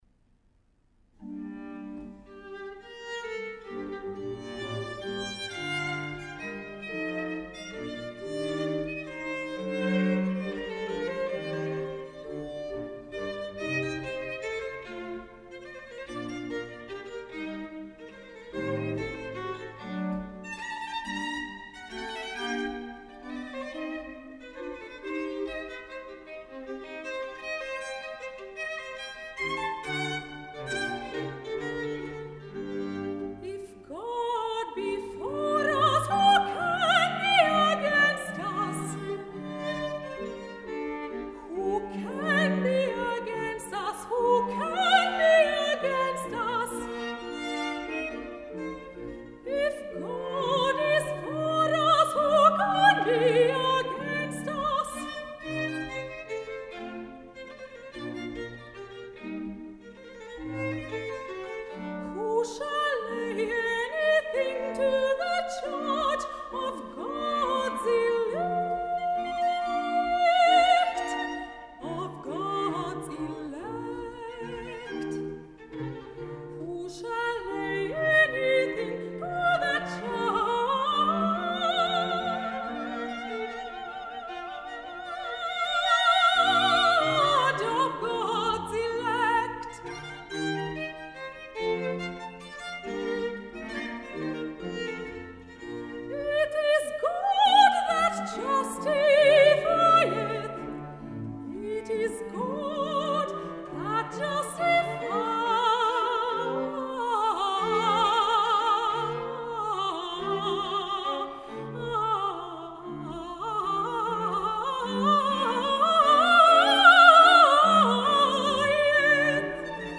Klangbeispiele (Orchester)
Messias-Konzerthaus.mp3